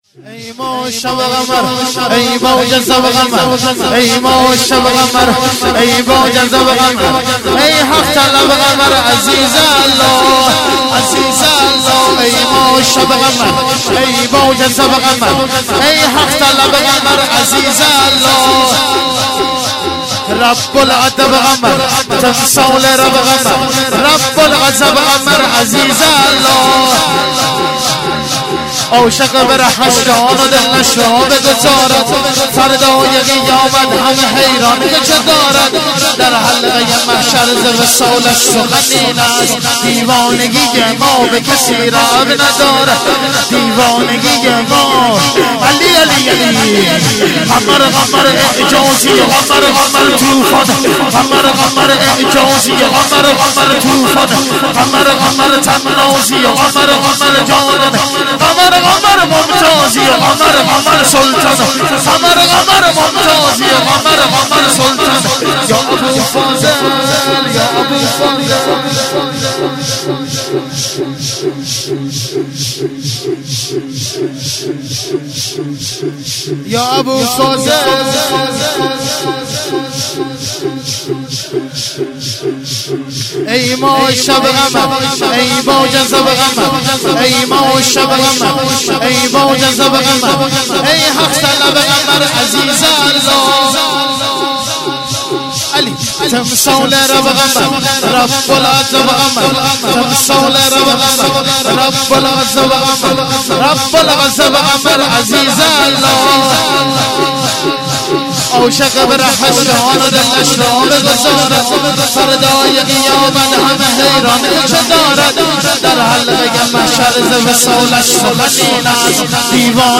هیئت زواراباالمهدی(ع) بابلسر - شور - ای ماه شب قمر